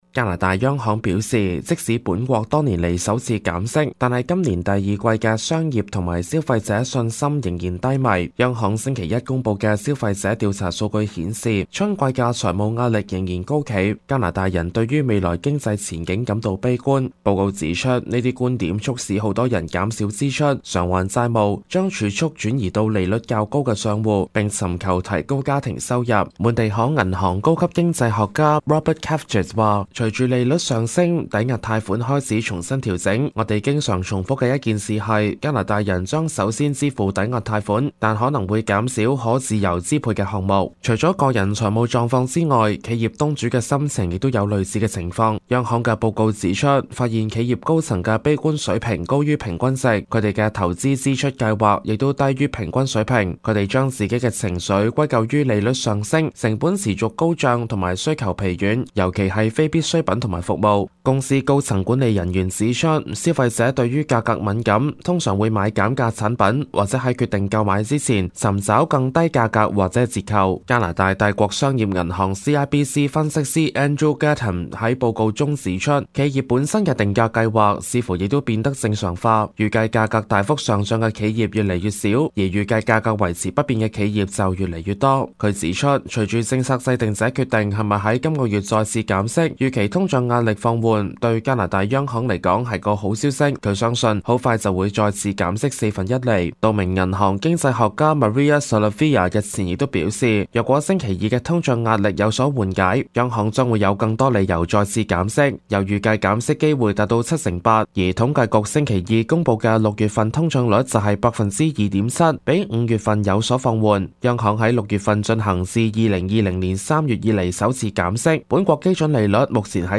news_clip_19721.mp3